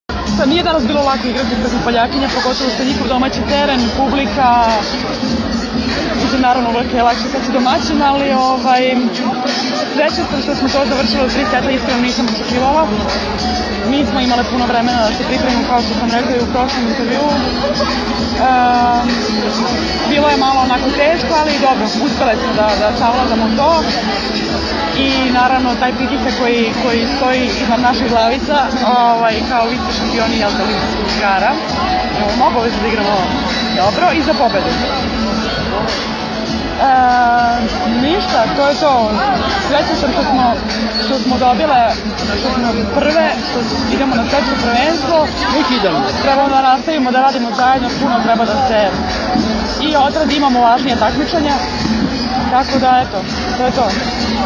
IZJAVA STEFANE VELJKOVIĆ